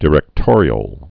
(dĭ-rĕktôrē-əl, dī-)